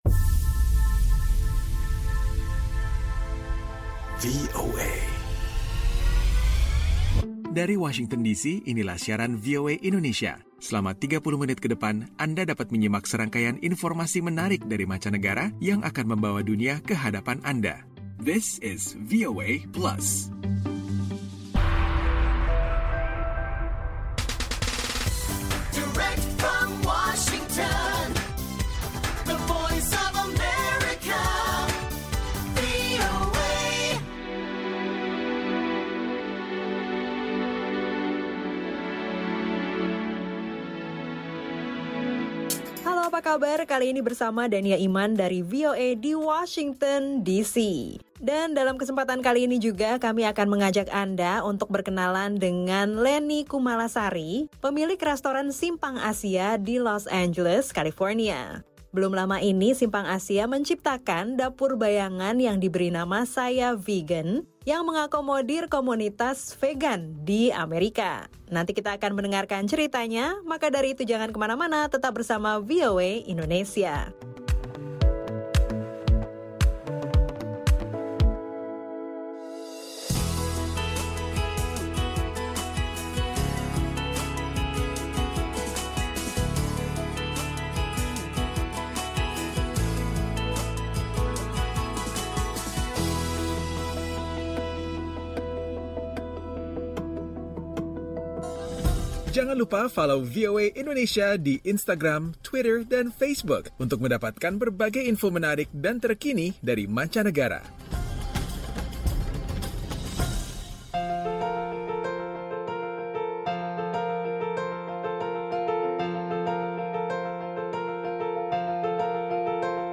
VOA Plus kali ini akan menampilkan obrolan dengan seorang Diaspora Indonesia yang akan berbagi tentang usaha restoran Indonesianya di kota Los Angeles. Ada pula info tentang teknologi keamanan yang dapat mendeteksi senjata.